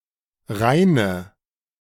Rheine (German: [ˈʁaɪnə]
De-Rheine.ogg.mp3